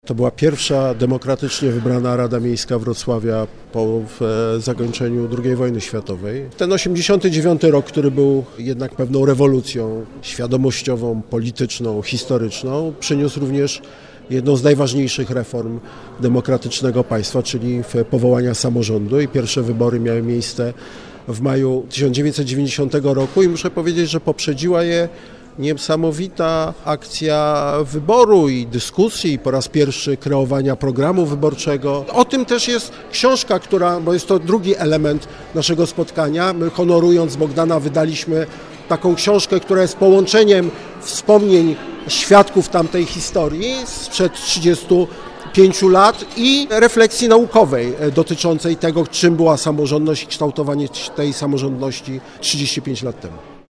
We wrocławskim Ratuszu odbyła się promocja książki pt. „Urządzamy Wrocław na nowo. Rada Miejska Wrocławia 1990-1994”.